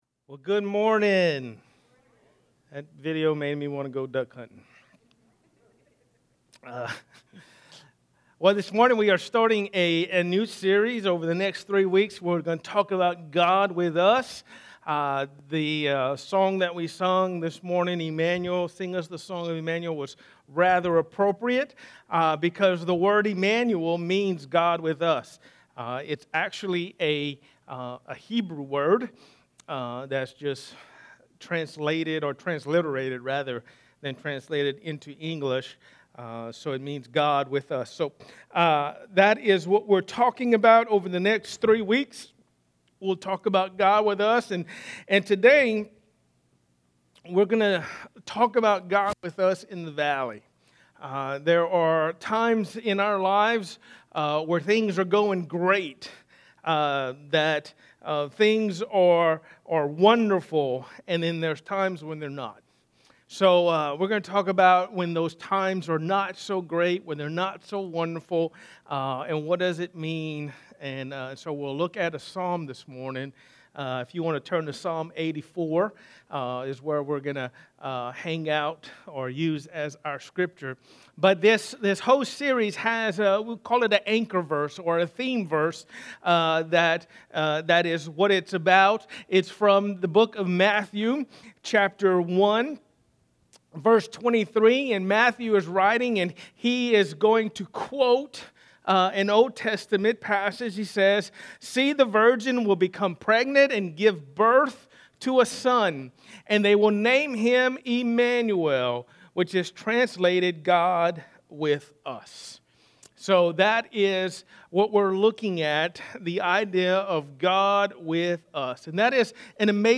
In this collection, the sermons are stand alone and not part of a particular series